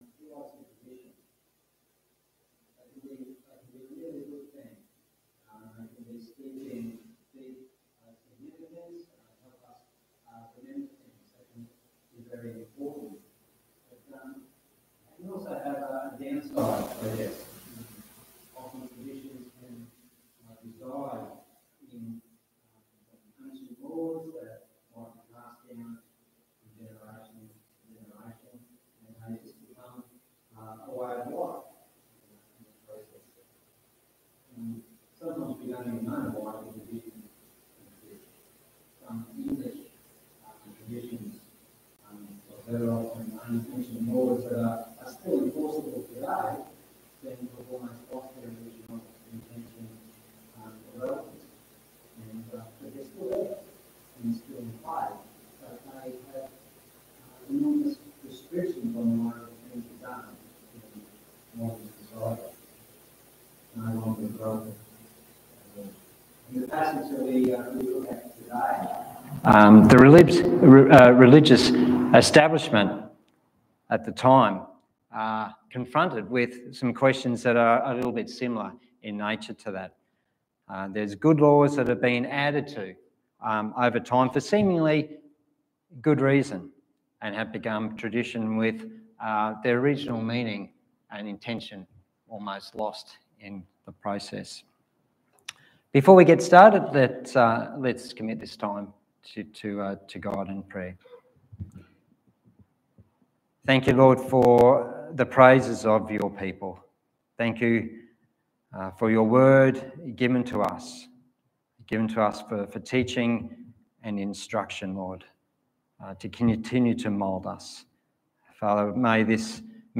Passage: Luke 5:33-6:16 Service Type: Sunday Morning